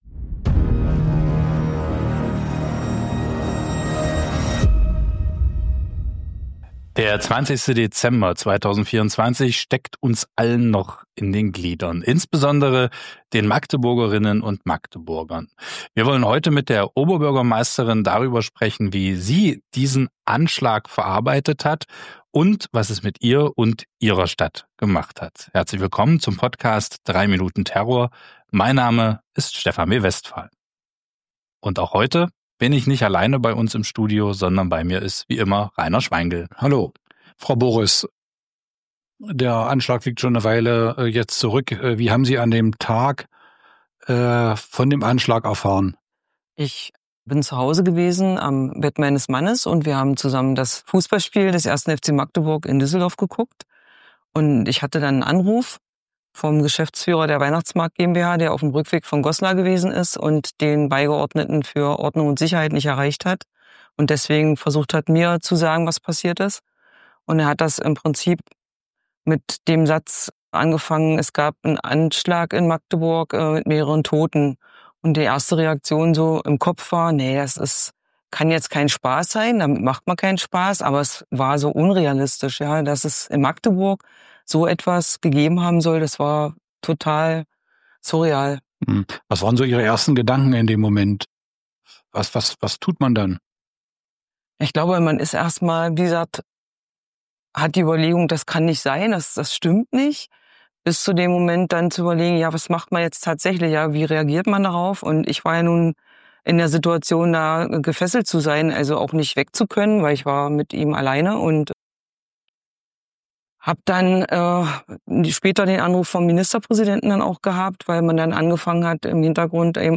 Dieses Gespräch bietet einen emotionalen Einblick in die Herausforderungen, mit denen die Stadtverwaltung und die Oberbürgermeisterin konfrontiert waren, als die neuesten Informationen über die Anzahl der Verletzten und Toten ans Licht kamen.